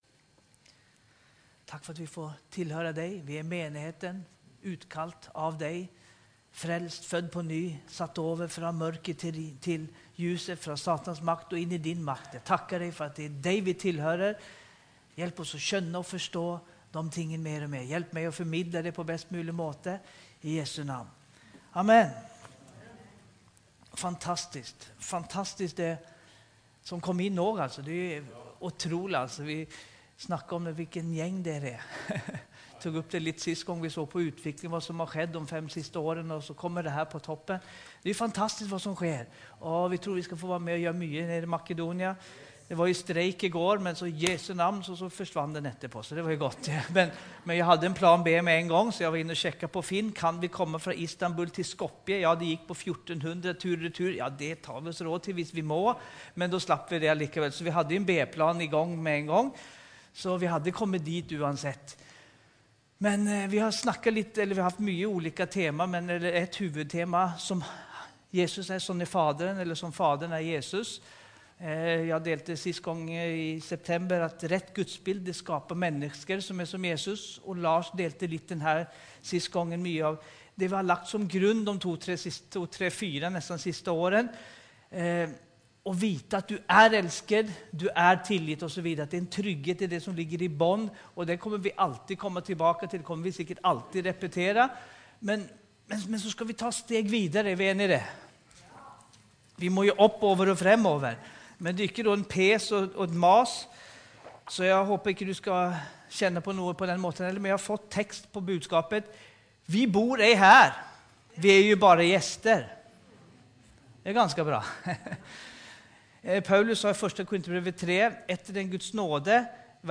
Evangeliehuset Romerike - Gudstjenester